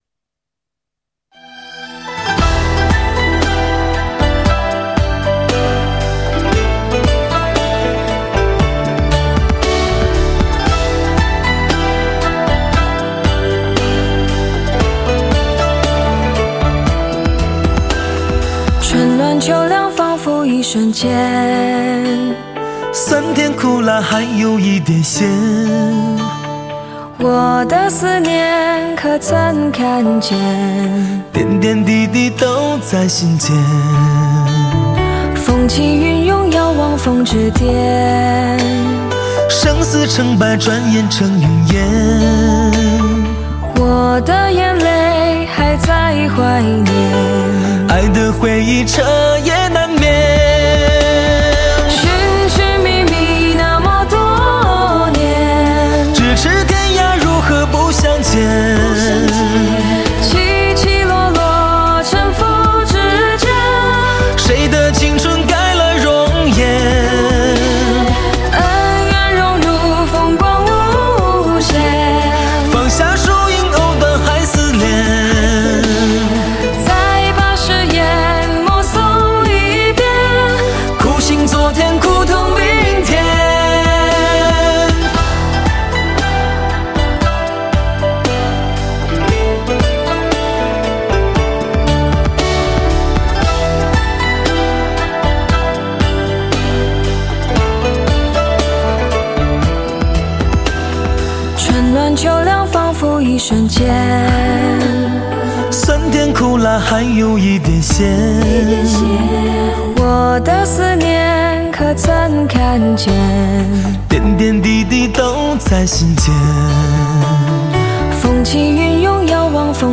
录音及其乐曲精致悦耳 呈现高品质的纹理与质感
男声独特的嗓音 男女声中的天然混合 犹如天籁之声